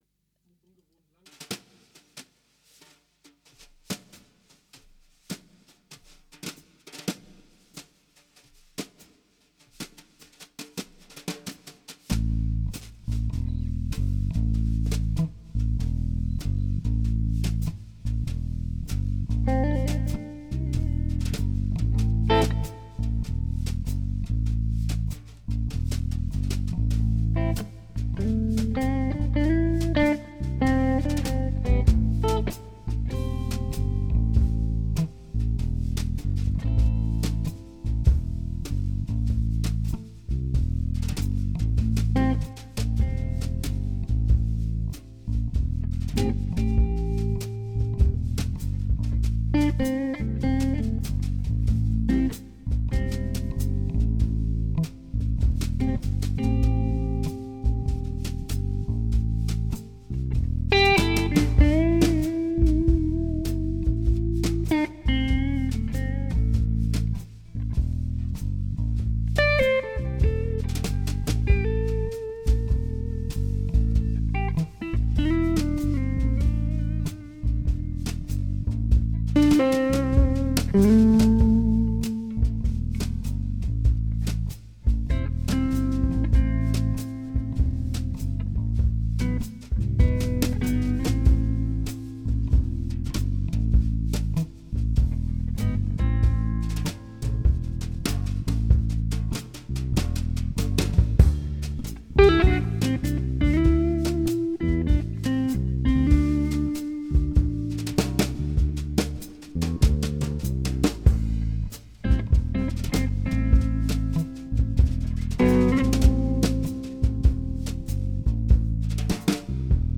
Klanghöhle Session vom 19.12.2016 – Blues
Drums
Git/Bass/Organ